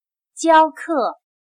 教课 / Jiāo kè /Enseñar